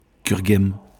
Cureghem (French, pronounced [kyʁəɡɛm]
Cureghem-FR.wav.mp3